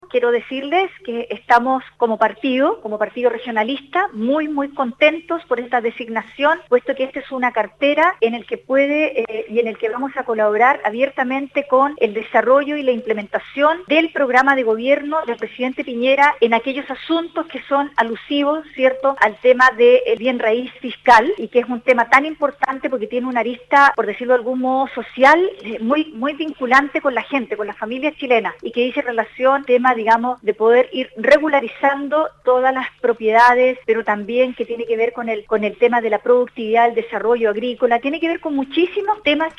Asimismo, la subsecretaria electa por la cartera de Bienes Nacionales, Alejandra Bravo comentó en conversación exclusiva con Radio Sago que están contentos como partido de la designación, ya que esta cartera busca colaborar en asuntos alusivos al bien raíz fiscal que se vincula con lo social, y por ende, con las familias chilenas.